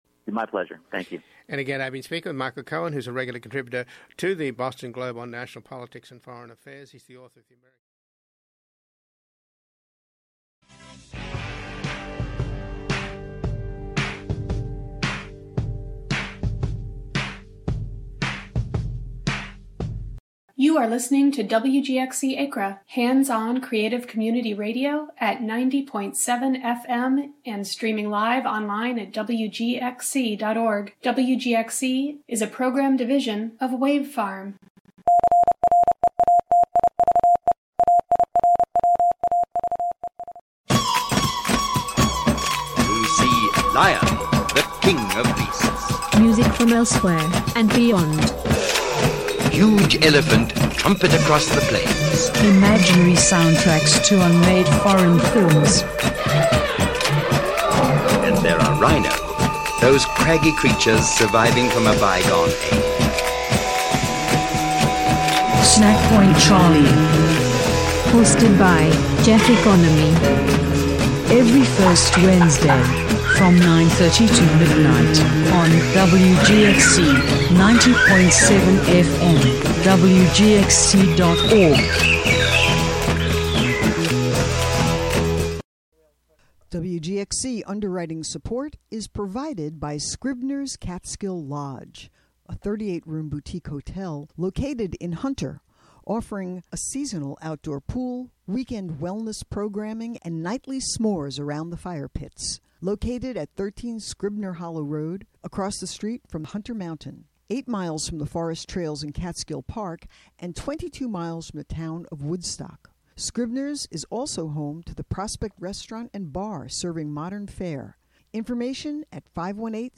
"All Together Now!" is a daily news show brought to you by WGXC-FM in Greene and Columbia counties.